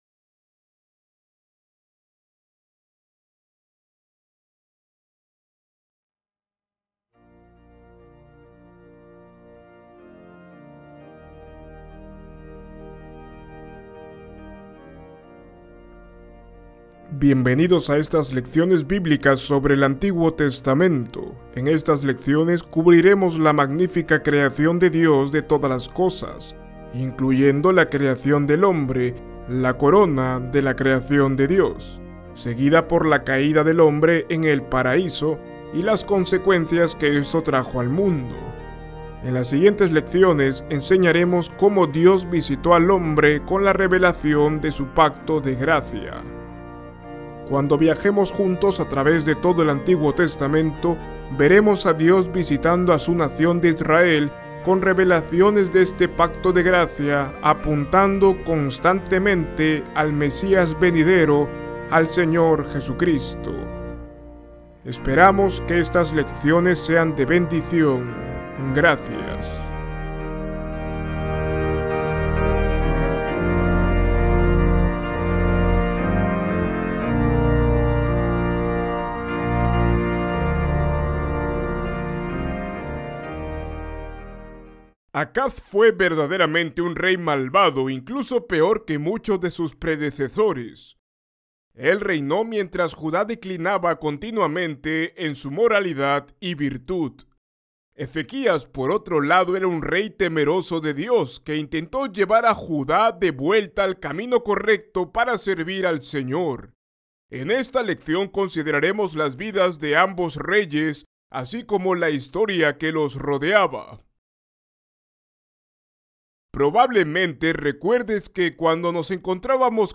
En esta lección veremos las vidas de estos dos reyes, y así como la historia que los rodea. Ver video Descargar video MP4 Escuchar lección Descargar audio en mp3 Ver transcripción en PDF Descargar transcripción en PDF Guia de Estudio